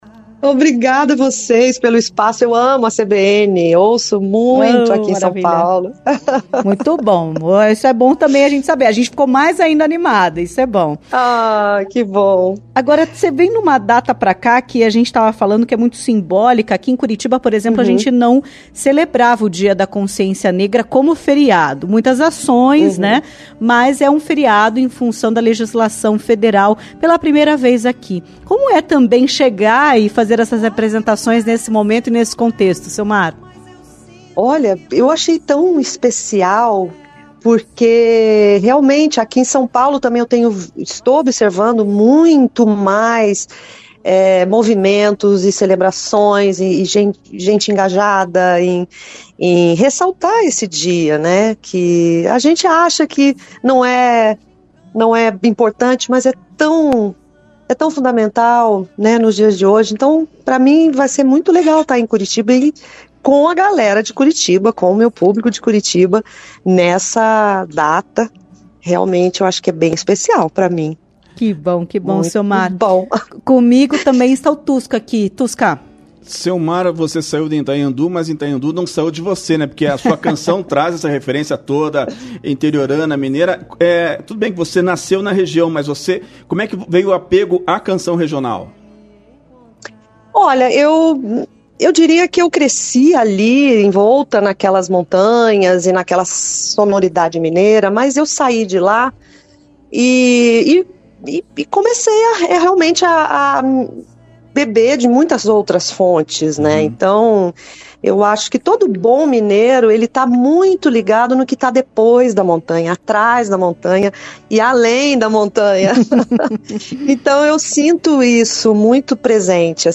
Em entrevista à CBN Curitiba, Ceumar destacou a carreira e parcerias.